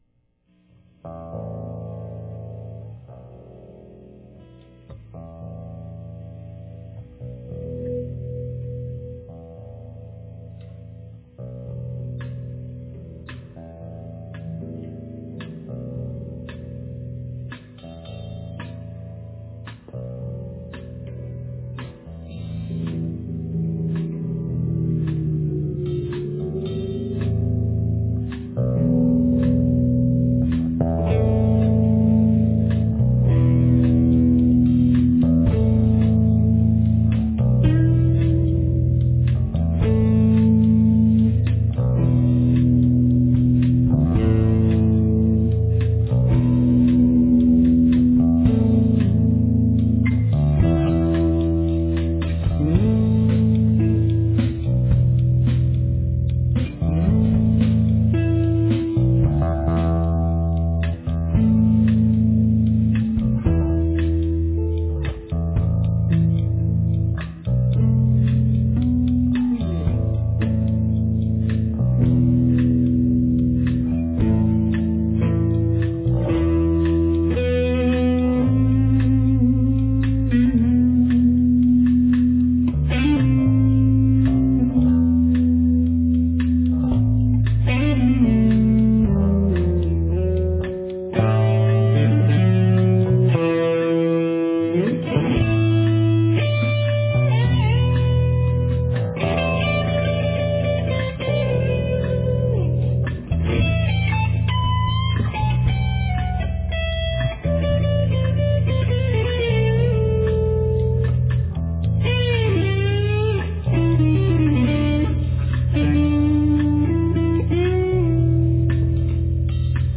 guitar
bass guitar
drums